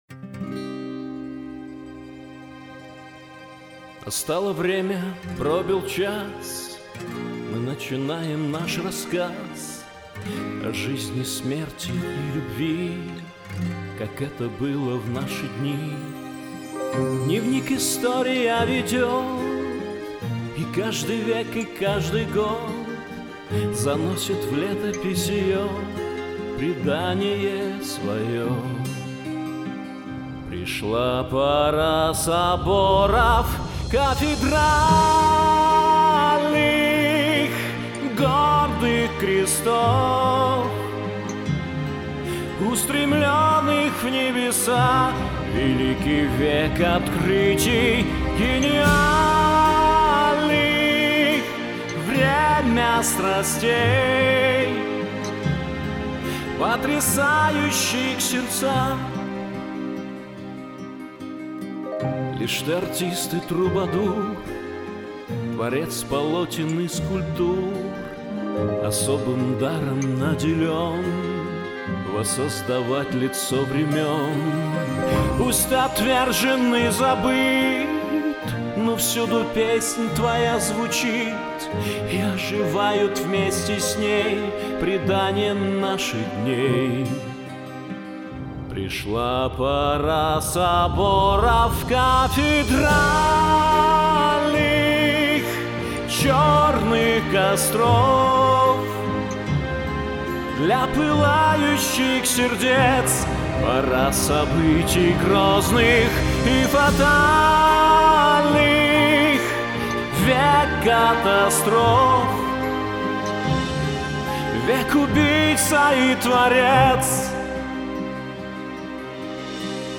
Очень красивые голоса, такие диапазоны!